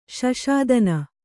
♪ śaśadana